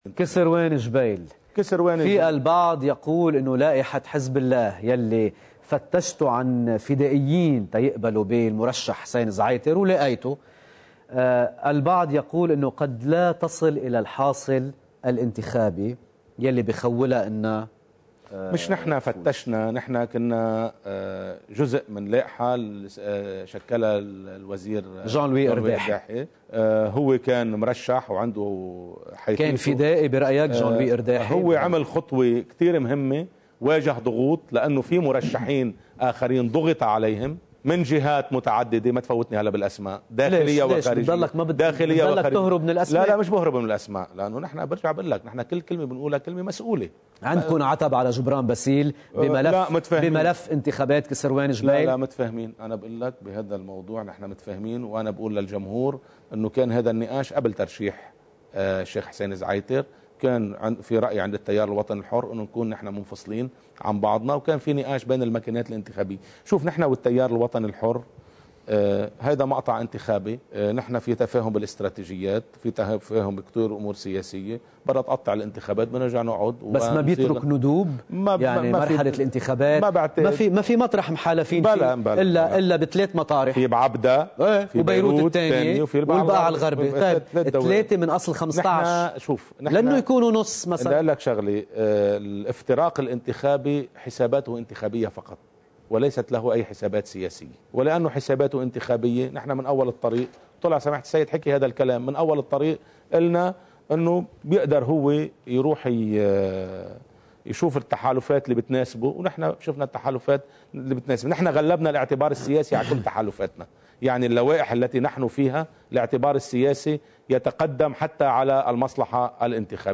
مقتطف من حديث النائب حسن فضل الله لقناة الجديد، ضمن برنامج “أسبوع في ساعة”: (1 نيسان 2018)